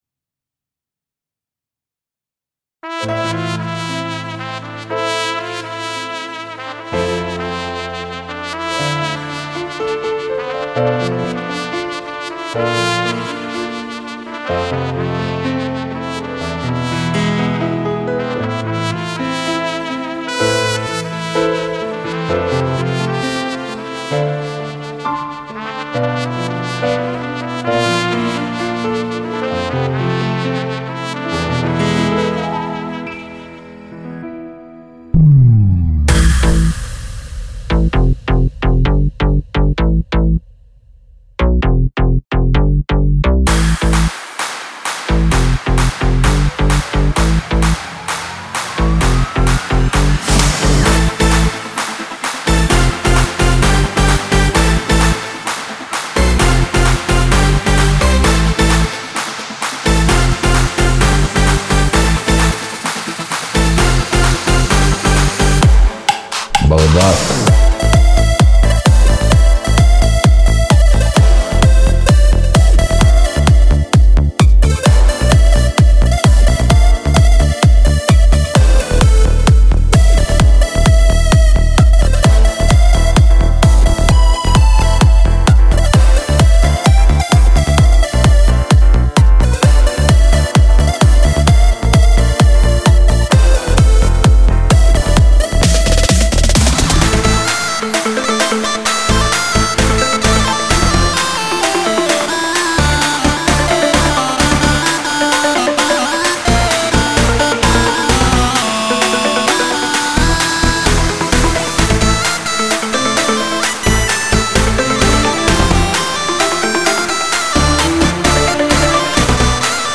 אני מעלה כאן קטע שלי על השיר הזה, בסגנון שונה קצת…